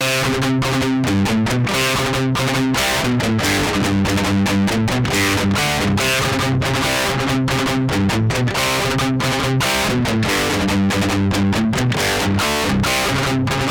Так по структуре перегруза вроде не сильно далеко от оригинала, в принципе похоже плюс-минус на мой взгляд, по частотке есть отличия конечно.